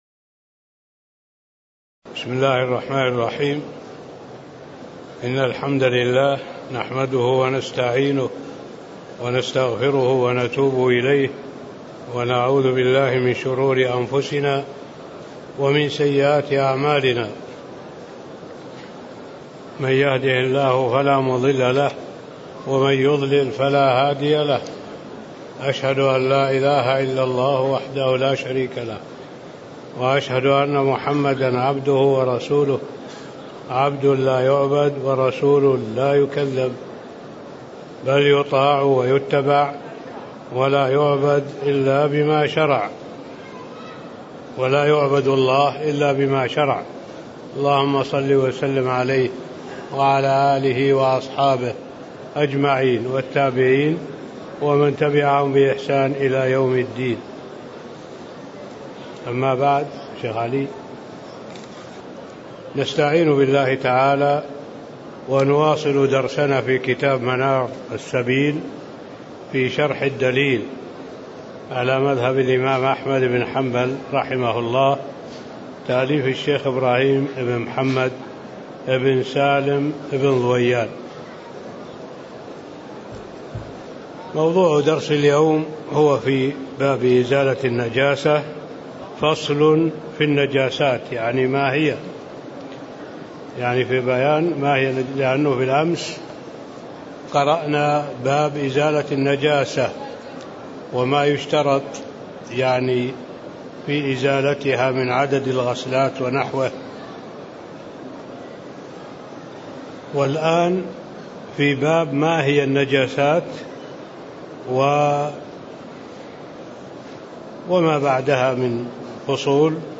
تاريخ النشر ١٧ جمادى الآخرة ١٤٣٦ هـ المكان: المسجد النبوي الشيخ